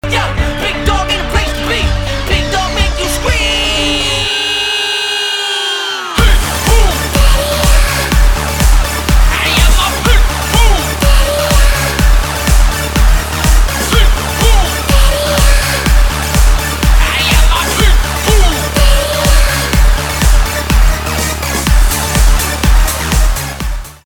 рэп
хип-хоп , битовые , басы
электроника